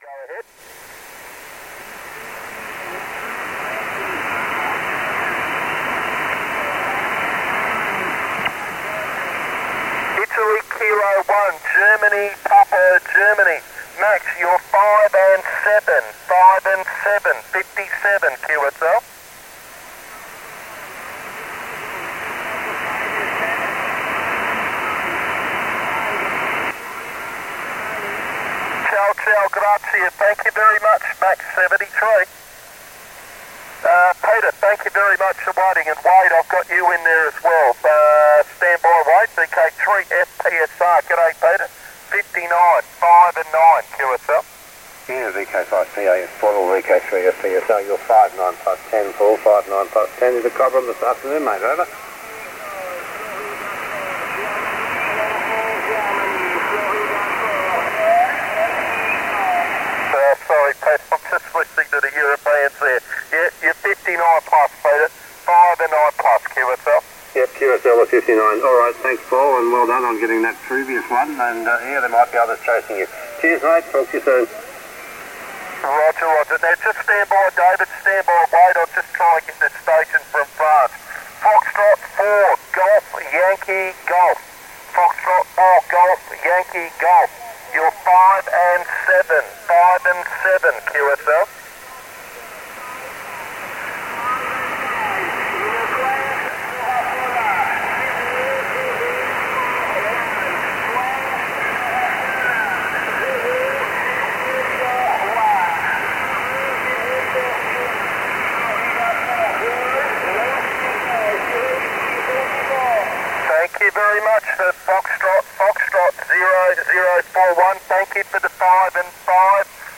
It was nearly 3.00 p.m. local time, and the 40m band was very busy, and as a result, it was very difficult to find a clear frequency.